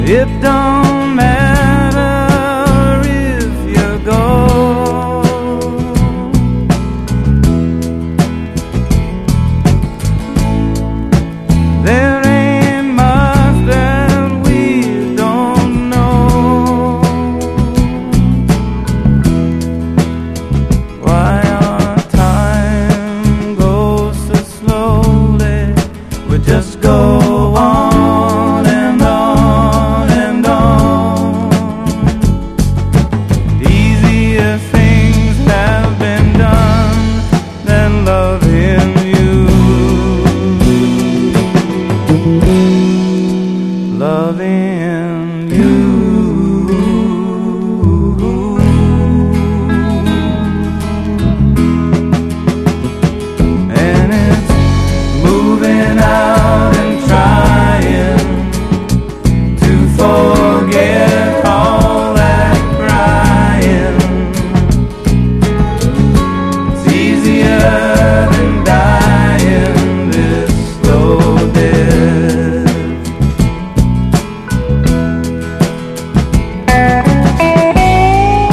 EASY LISTENING / OTHER / EXERCISE / DRUM BREAK / EDUCATION
ドラム・ブレイクあり！
と言ってもスポークンものではなくベース、ドラム、ピアノでのシンプルな構成によるダンス・フロア・ジャズを収録！